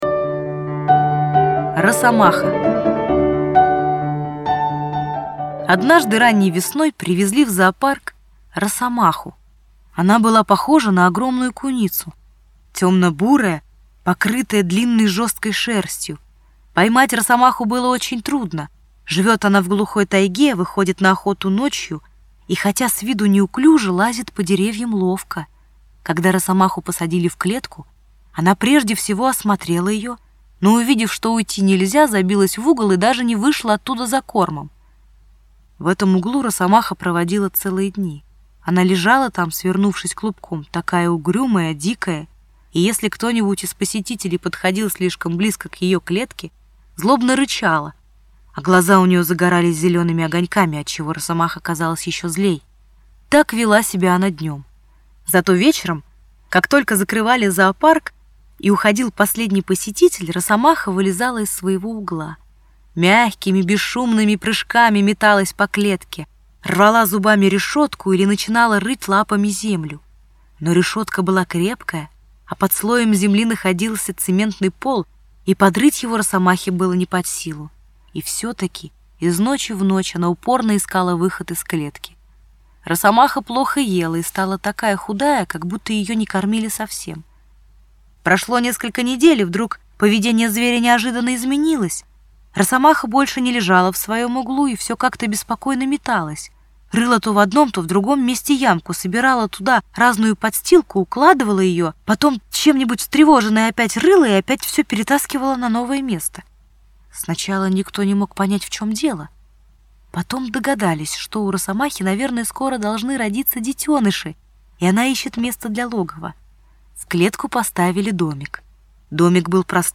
Аудиорассказ «Росомаха»